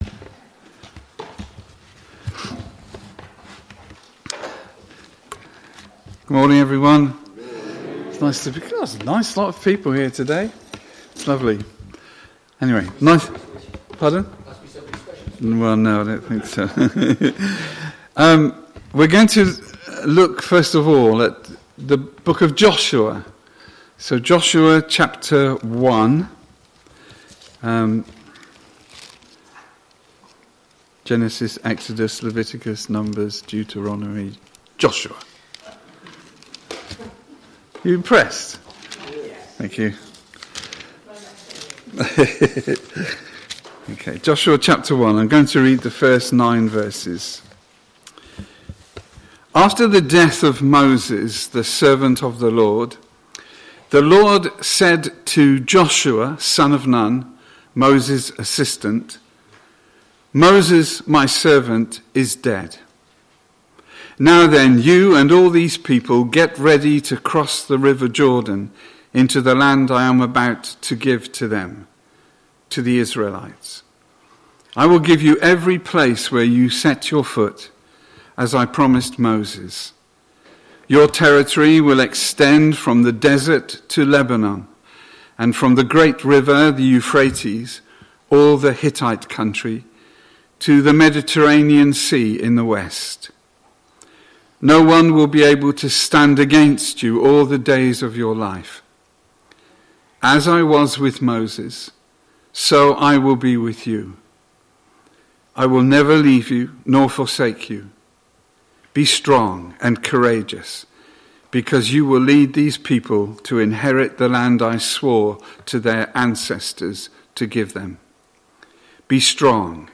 SUNDAY-SERVICE-29.06.2025.mp3